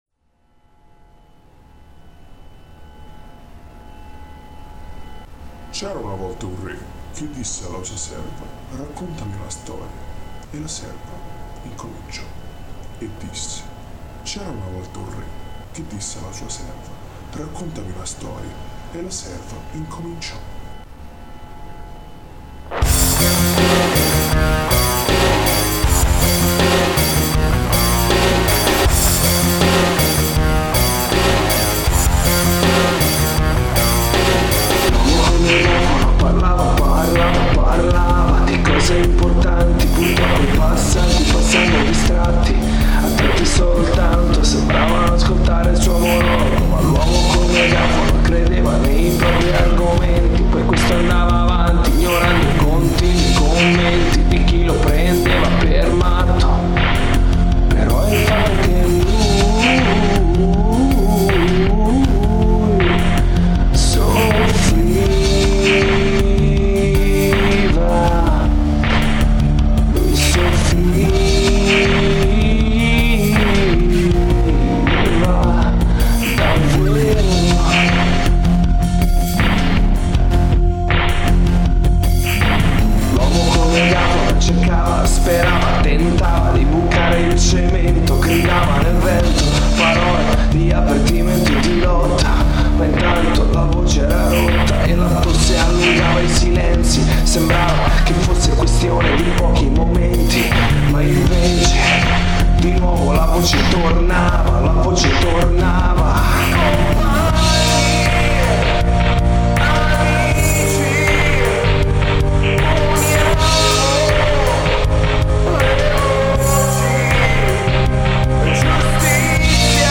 cover casalinga